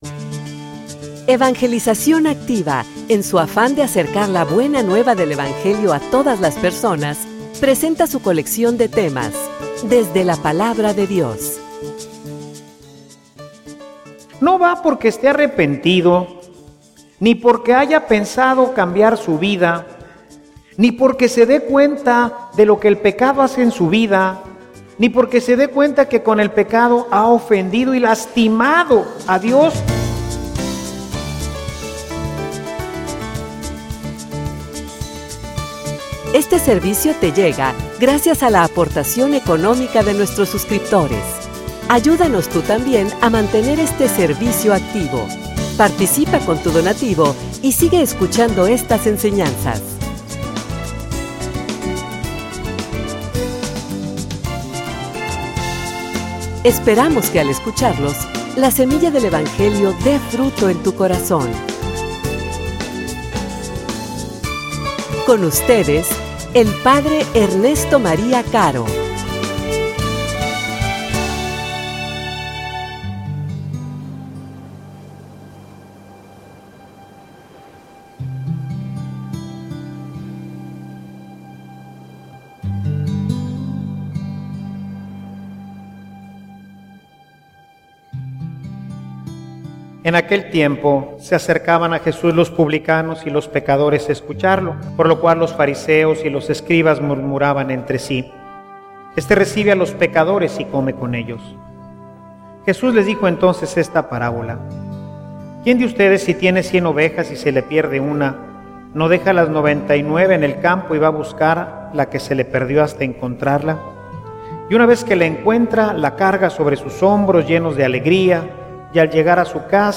homilia_Sin_doblez_de_corazon.mp3